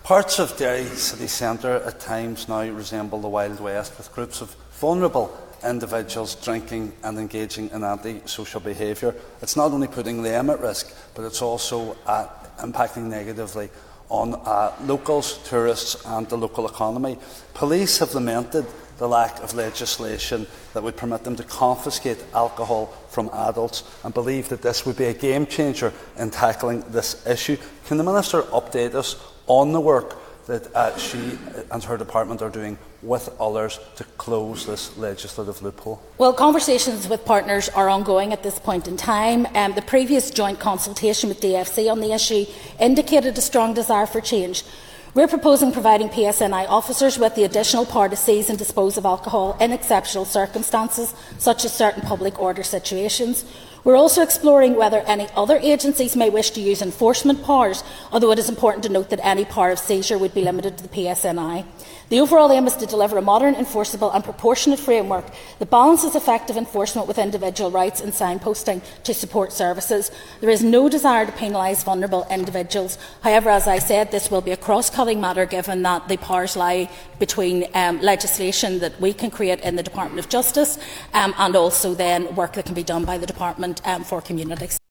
The North’s Justice Minister Naomi Long says efforts are ongoing: